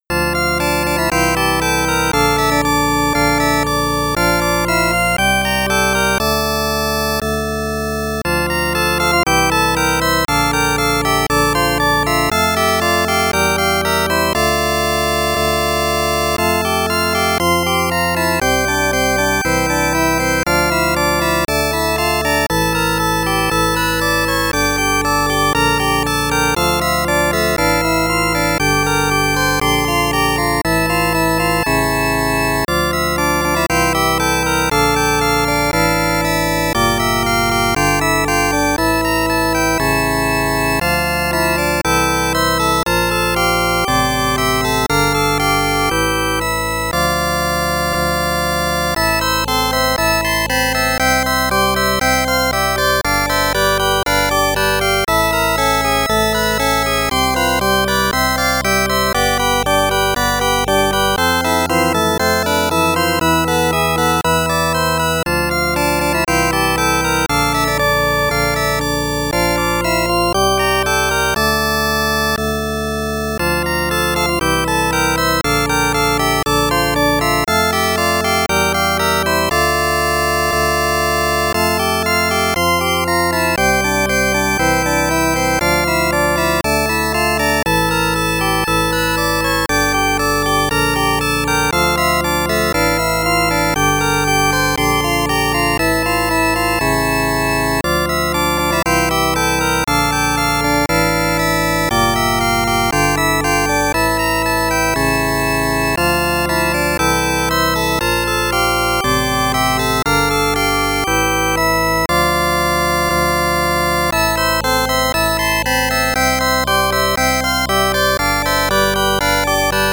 Warning - The MP3 is quite loud!